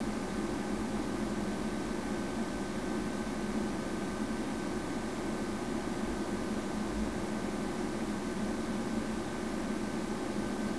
The fans hummed, the drives whirred.
Nevertheless, I was determined to be at least vaguely scientific about this review, so I pulled out the omnidirectional microphone I had borrowed from the media unit at work and started making recordings.
Before I had applied the Dynamat Extreme Computer Kit, I had taken three 10 second recordings: one at the front left of the machine, one at the front right, and one at the direct rear.